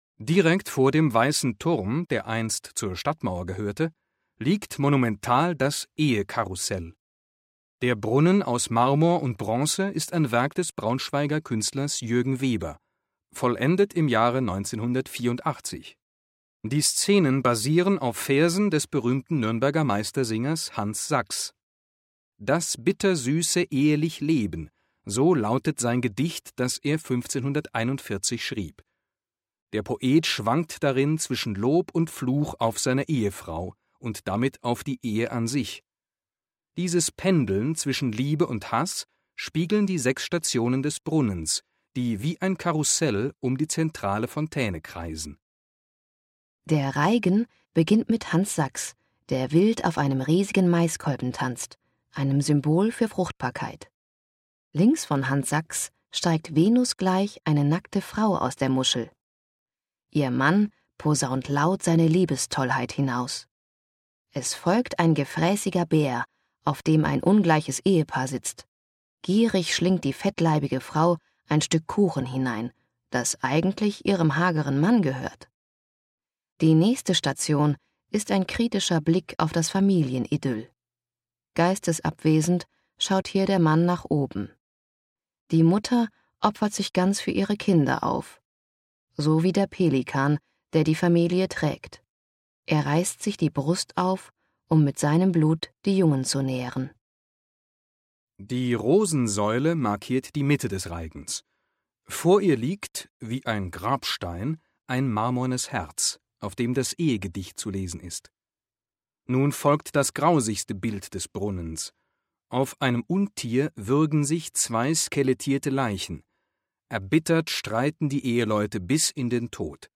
ehekarussell_audioguide.mp3